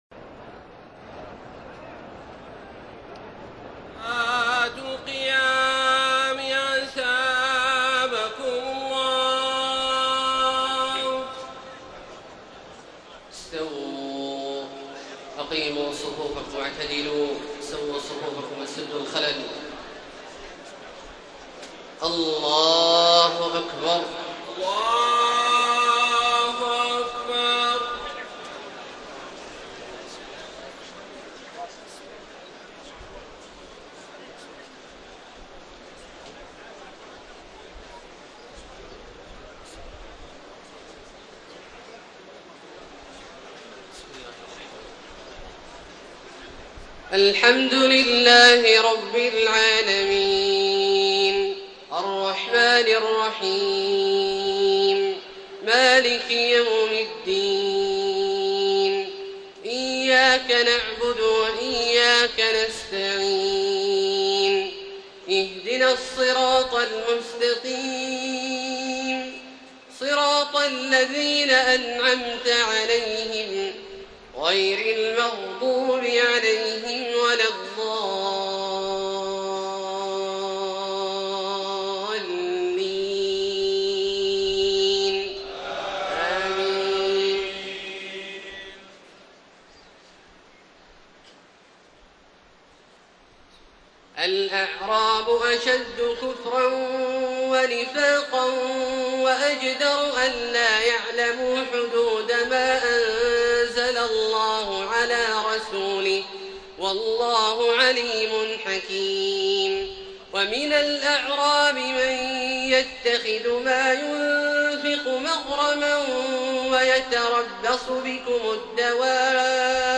تهجد ليلة 30 رمضان 1433هـ من سورتي التوبة (97-129) و يونس (1-52) Tahajjud 30 st night Ramadan 1433H from Surah At-Tawba and Yunus > تراويح الحرم المكي عام 1433 🕋 > التراويح - تلاوات الحرمين